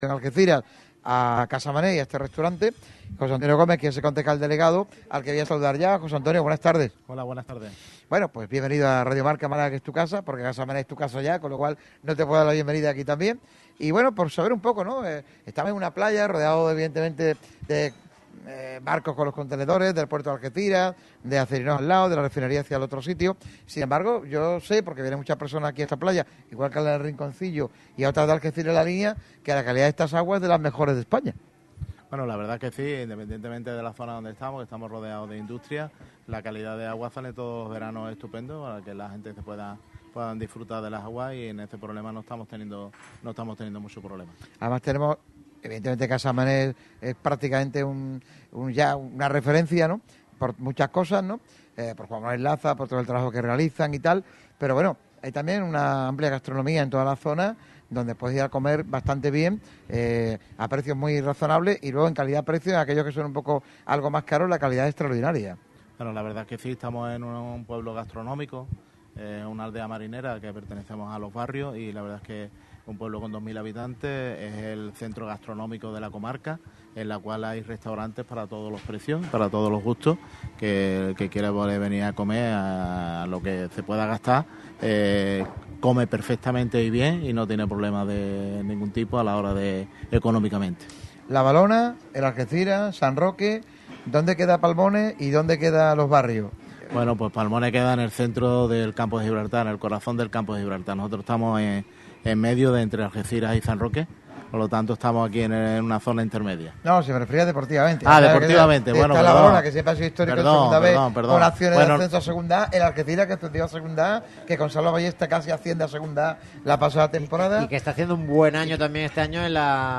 José Antonio Gómez, concejal de Playas en el Ayuntamiento de Los Barrios, ha sido uno de los grandes invitados en Casa Mané, en una tertulia agradable, con distintos temas encima de la mesa, además de los manjares que sirven en una de las referencias gastronómicas de la zona. Se ha hablado por supuesto de fútbol, pero el edil ha aprovechado la ocasión para presumir del municipio en general y de Palmones en particular.